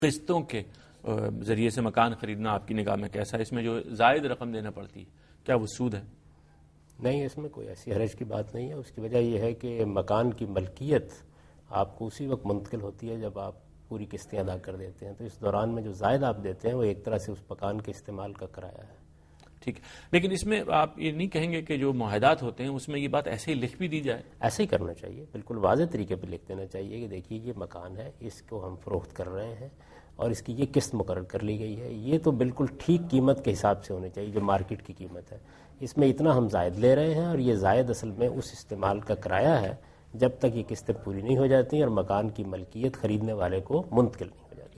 Answer to a Question by Javed Ahmad Ghamidi during a talk show "Deen o Danish" on Duny News TV
دنیا نیوز کے پروگرام دین و دانش میں جاوید احمد غامدی ”قسطوں پر مکان لینا“ سے متعلق ایک سوال کا جواب دے رہے ہیں